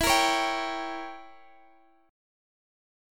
Gdim/E chord